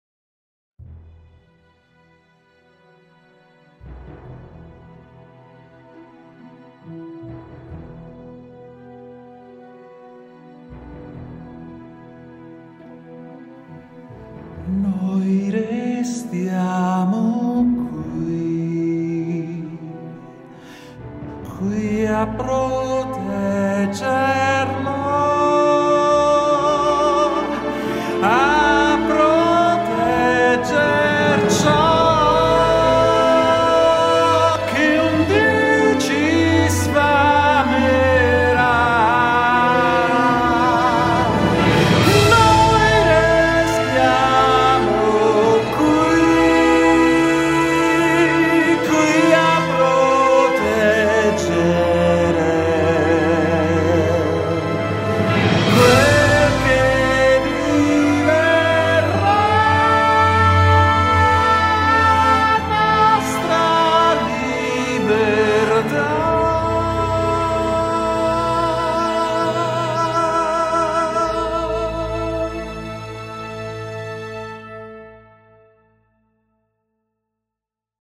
UOMINI
tenore.mp3